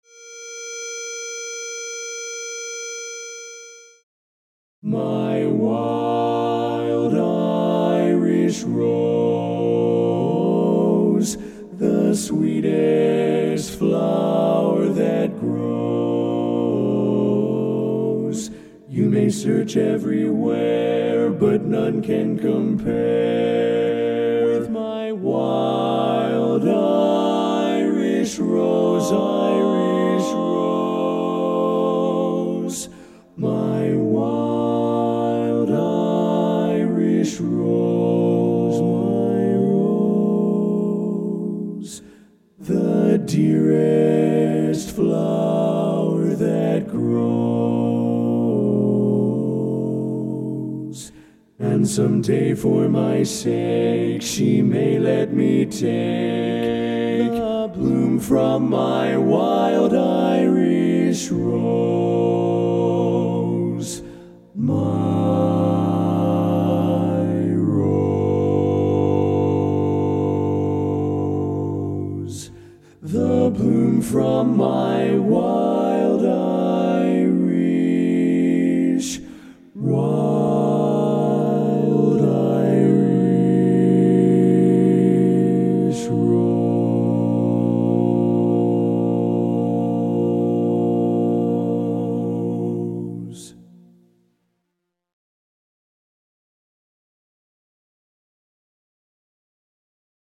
Kanawha Kordsmen (chorus)
Ballad
B♭ Major
Bari